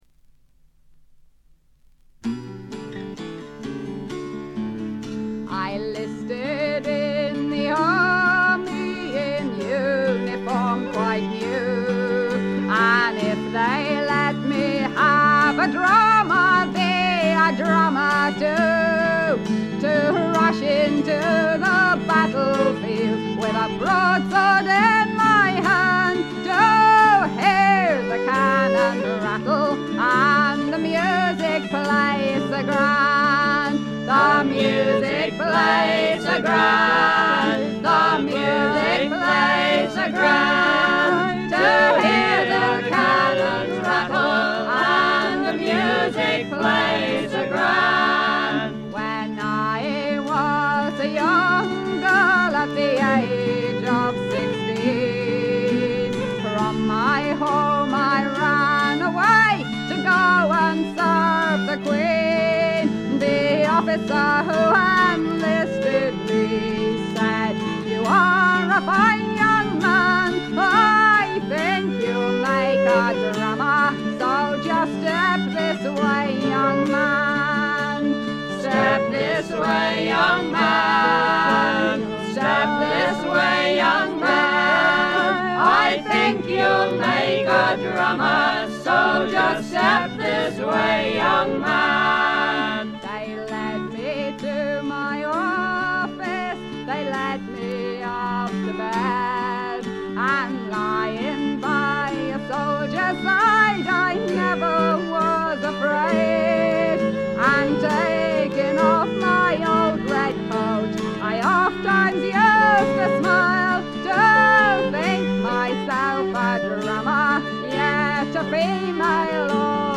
本作でも無伴奏もしくは最小限のバッキングを従えての歌唱は、尋常ではない美しさと強靭さを兼ね備えています。
試聴曲は現品からの取り込み音源です。
acc. fiddle, guitar, dulcimer, with chorus
vocals
fiddle
guitar
Appalachian dulcimer